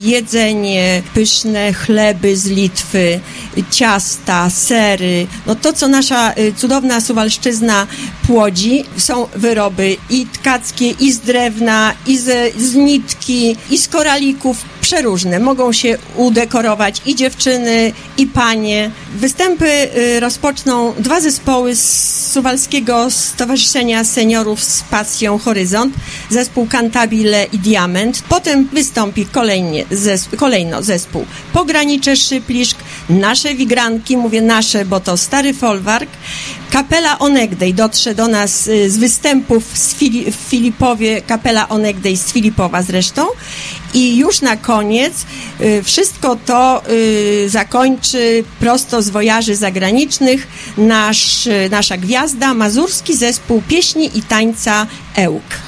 Na miejscu są też nasi reporterzy, którzy na żywo zdają relacje z wydarzenia. W przyklasztornych ogrodach przez cały dzień trwa wielka kupiecka wrzawa.